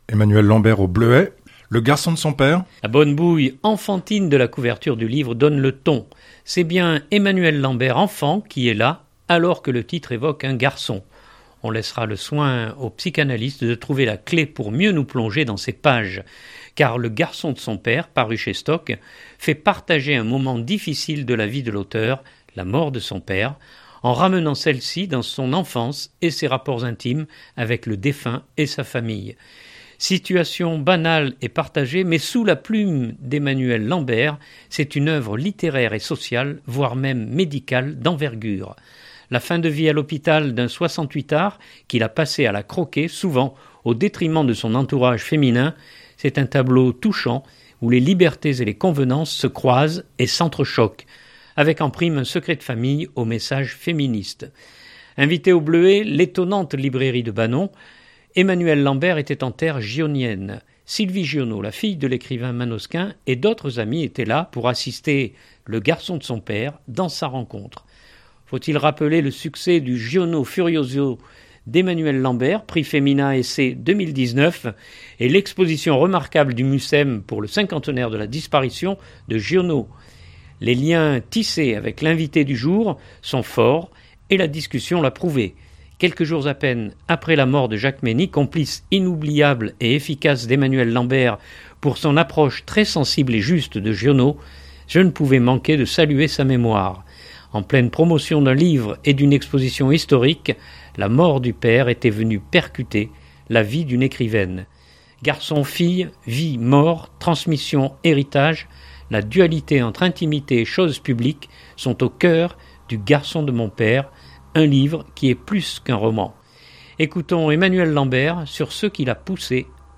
Invitée au Bleuet, l’étonnante librairie de Banon, Emmanuelle Lambert était en terre gionienne.
Les liens tissés avec l’invitée du jour sont forts et la discussion l’a prouvé.